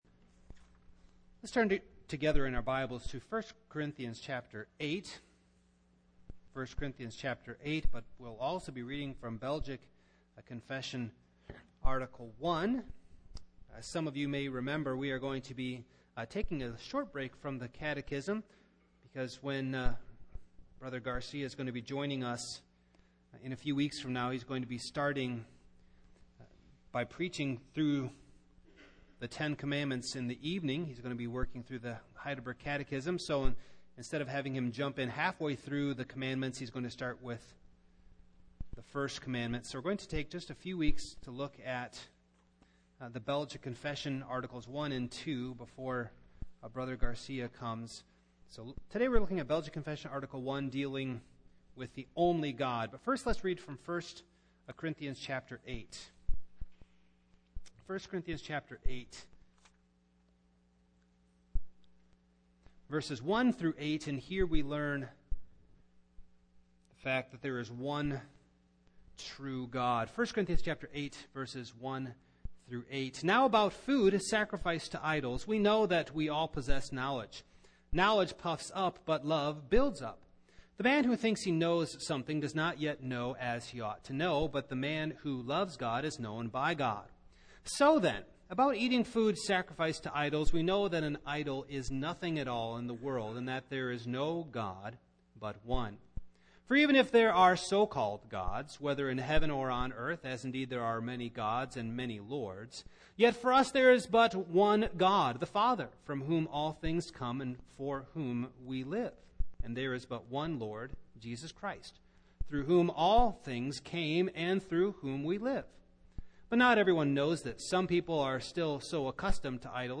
Single Sermons
Service Type: Evening